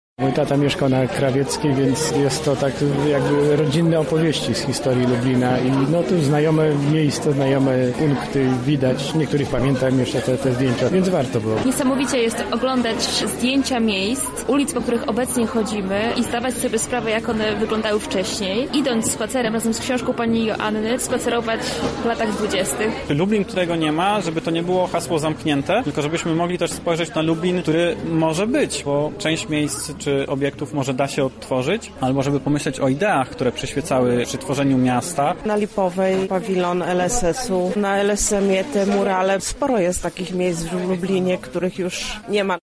Uczestnicy opowiedzieli o swoich wrażenia z premiery książki: